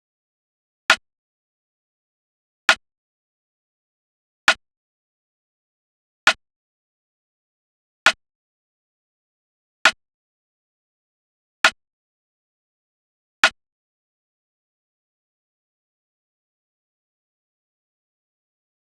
drill (!)_[PBS] Compressed Snare.wav